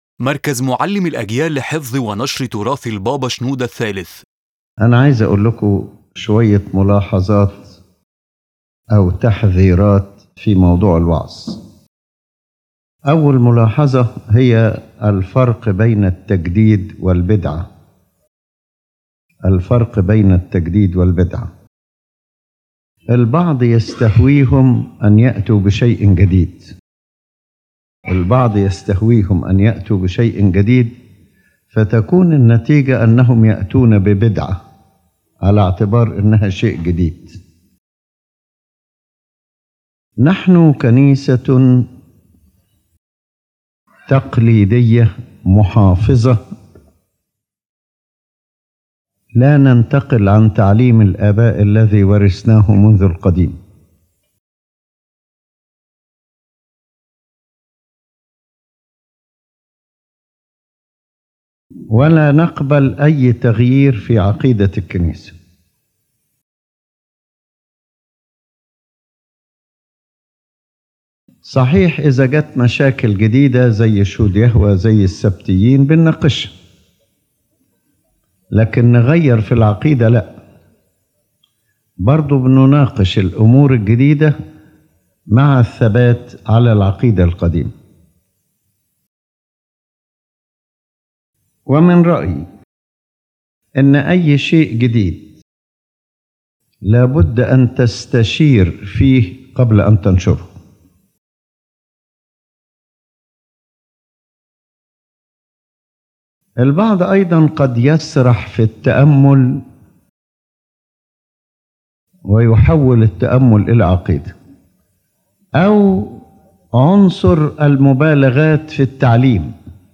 In this lecture, His Holiness Pope Shenouda III presents a series of educational and spiritual warnings to preachers, cautioning against deviating from Orthodox teaching due to personal opinions, exaggerations, reliance on non-Orthodox sources, or interpreting verses in a fragmented way. He affirms that the Church is steadfast in its tradition and faith, and that any renewal must be disciplined and referred back to the shepherds and apostolic teaching.